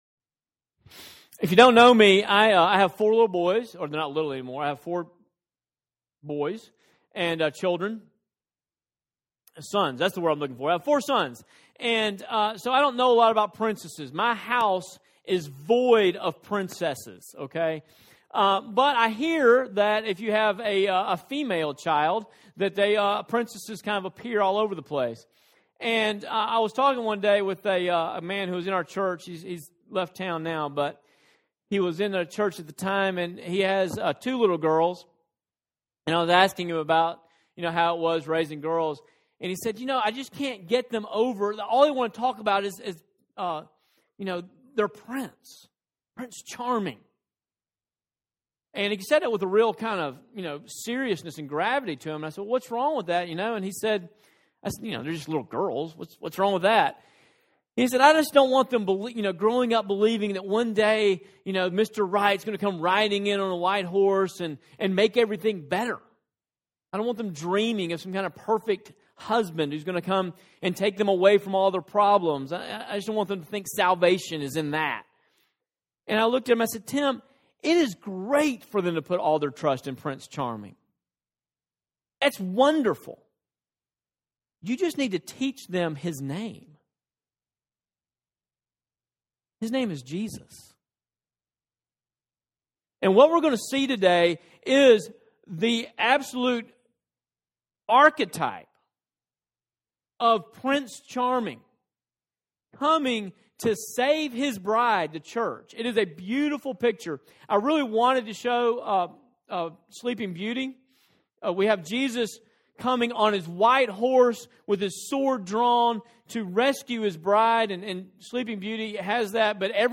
Reformed Sermons Sermons & Lectures by Series